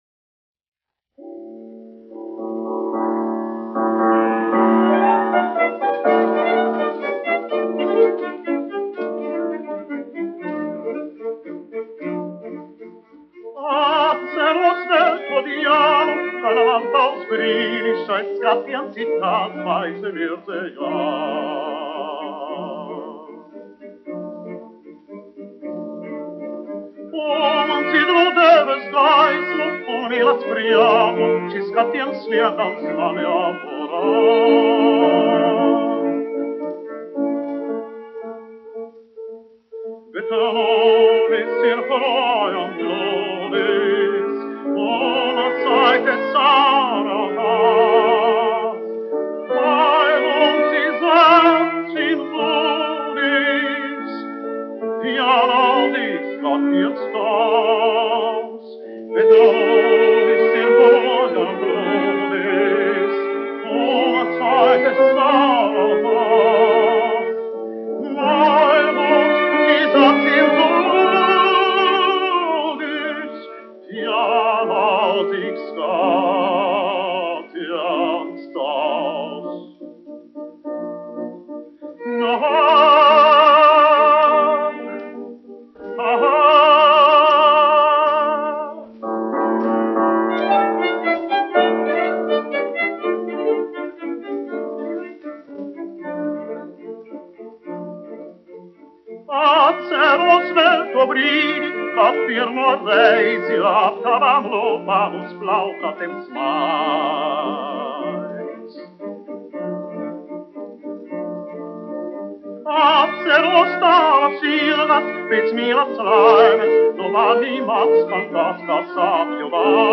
1 skpl. : analogs, 78 apgr/min, mono ; 25 cm
Dziesmas (augsta balss) ar instrumentālu ansambli
Skaņuplate